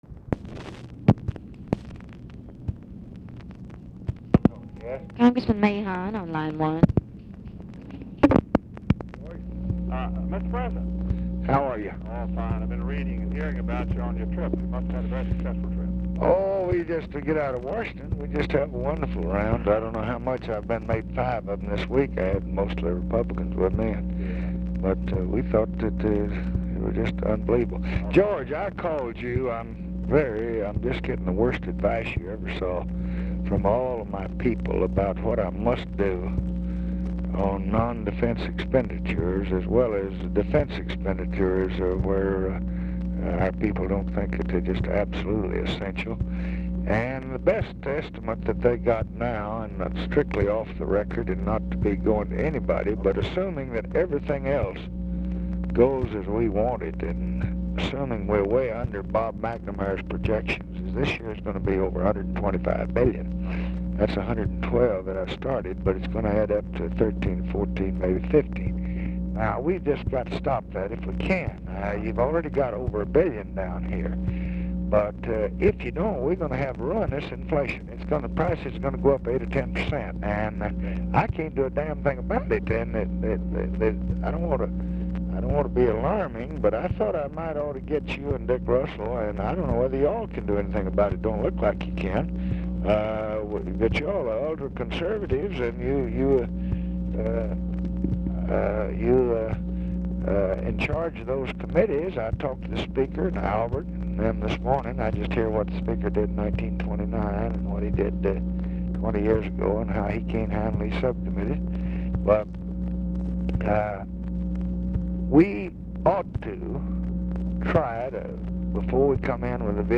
POOR SOUND QUALITY; MAHON IS HARD TO HEAR; CONTINUES ON NEXT RECORDING
Format Dictation belt
Location Of Speaker 1 Oval Office or unknown location
Specific Item Type Telephone conversation